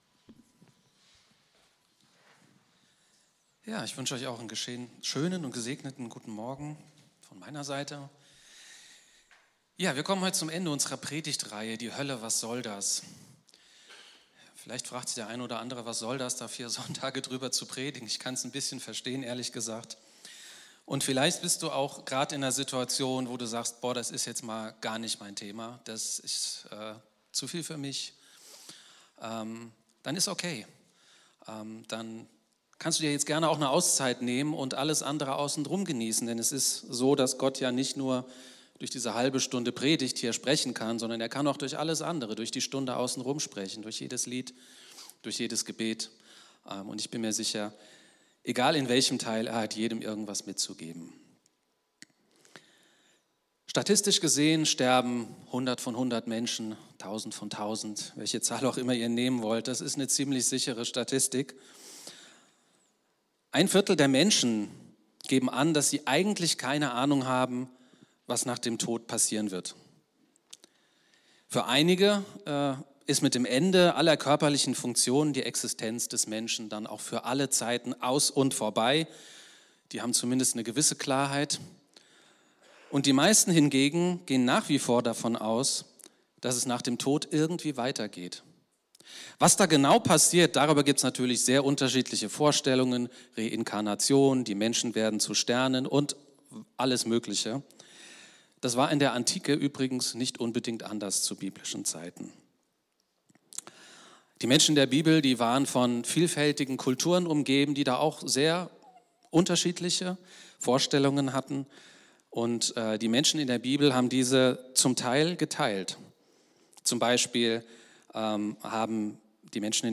Gottesdienst vom 25.08.2024 Hölle – Was soll das?! (Teil 4/4)